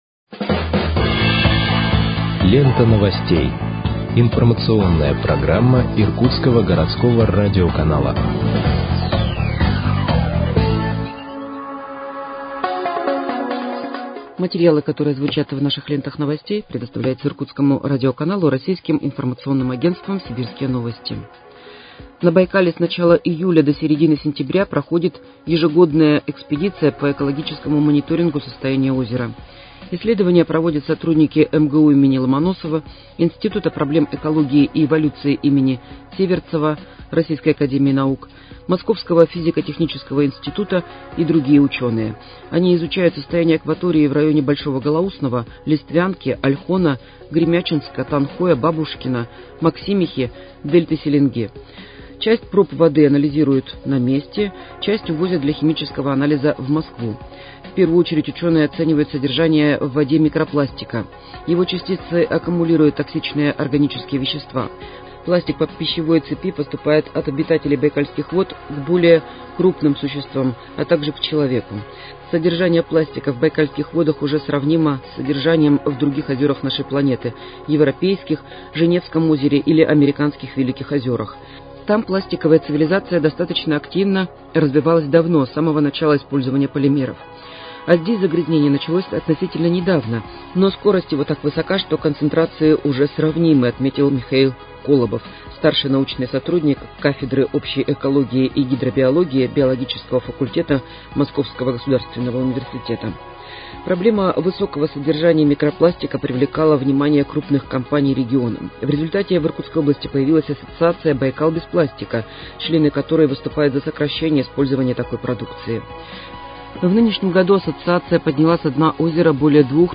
Выпуск новостей в подкастах газеты «Иркутск» от 06.09.2023 № 1